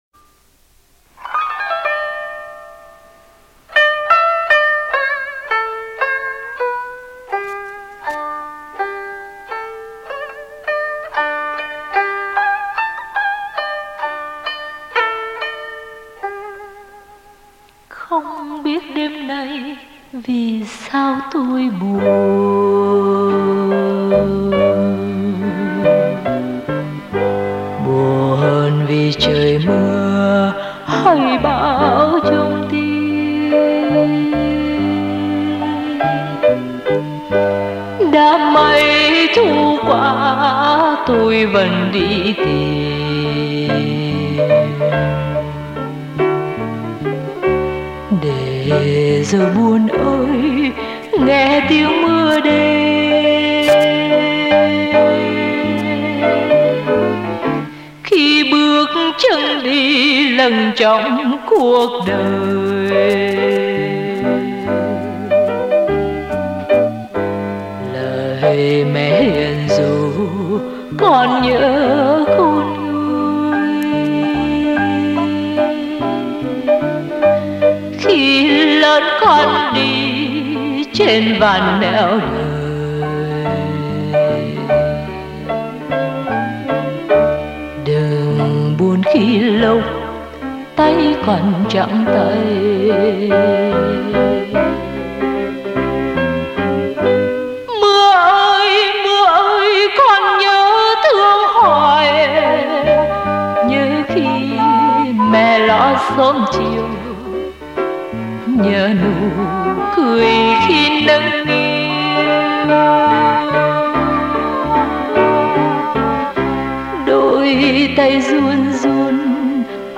Dòng nhạc tuy đơn giản nhưng thật nhẹ nhàng êm ái.